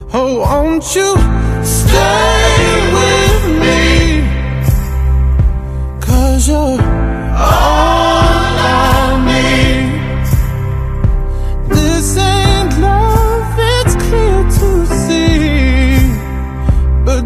This is a sound sample from a commercial recording.
Reduced quality: Yes